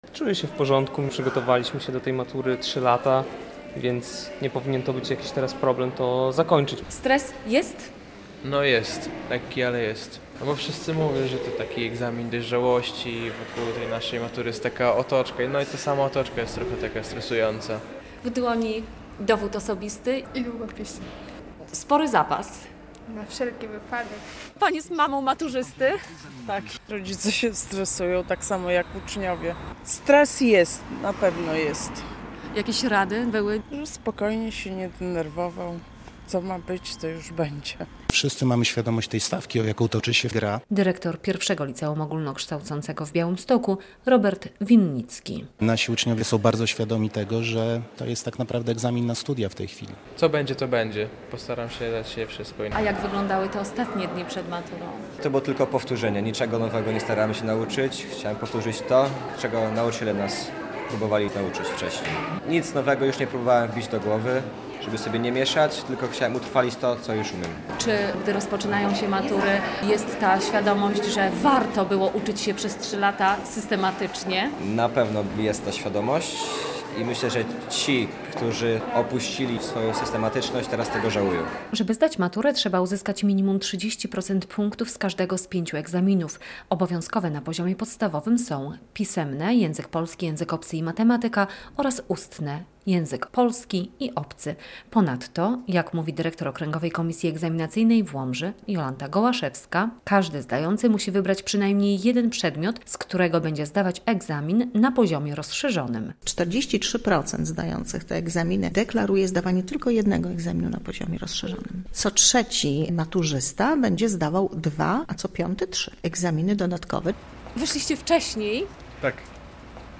Maturzyści po egzaminie z j. polskiego, czas na matematykę - relacja